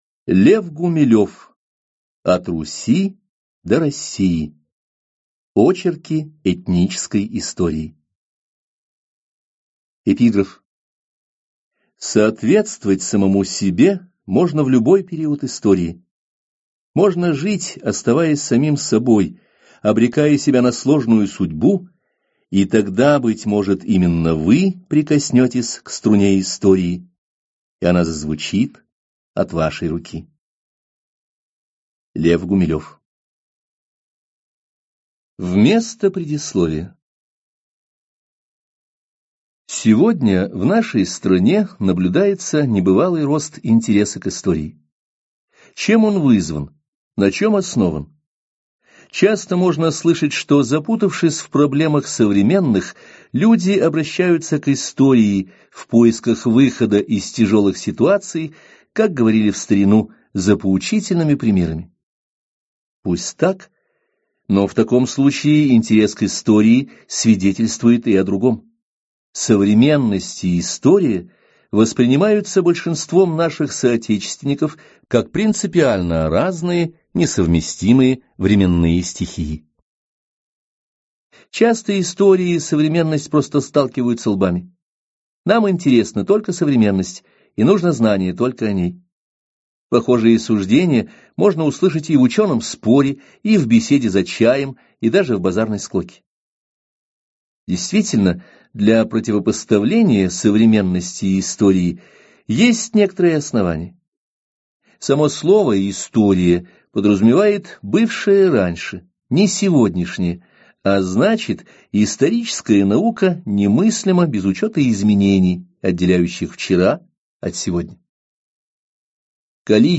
Аудиокнига От Руси до России | Библиотека аудиокниг
Прослушать и бесплатно скачать фрагмент аудиокниги